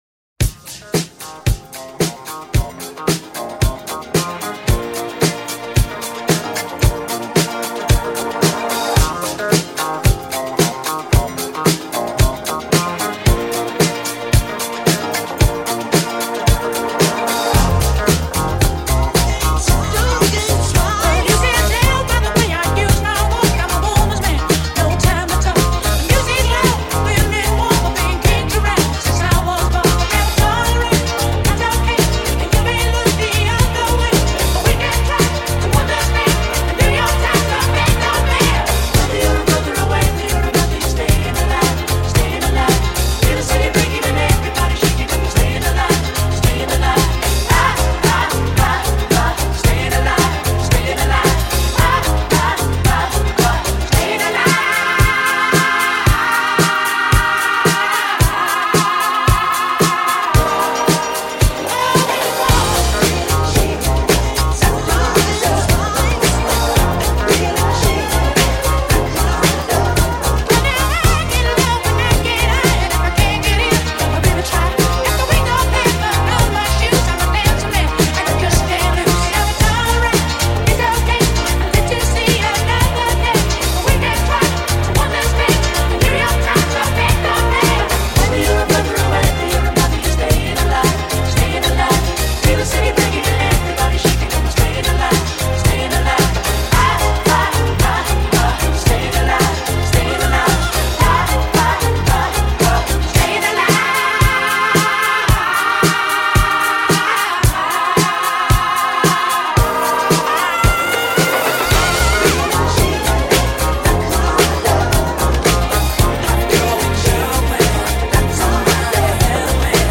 Вот такой у меня есть может пригодиться Mix